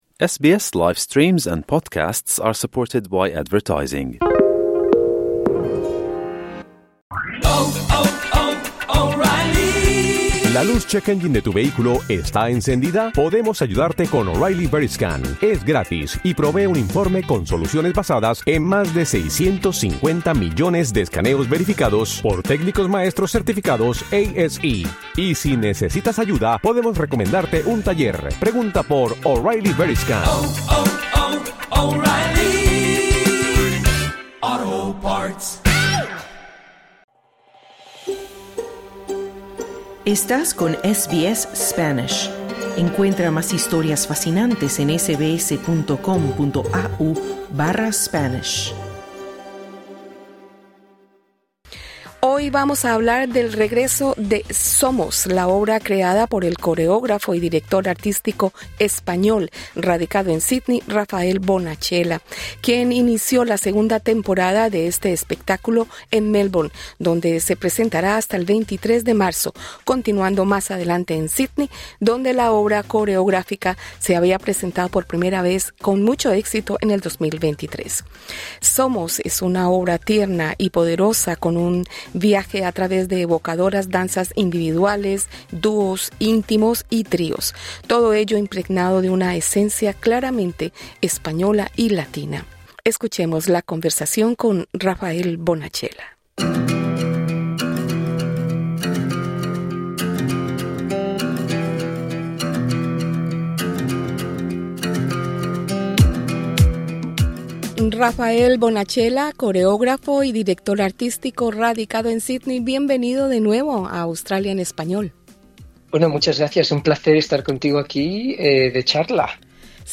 Entrevista con el coreógrafo y director artístico español Rafael Bonachela, quien presenta en Melbourne y en Sídney Somos, una obra que tuvo su estreno mundial en Sídney en 2023.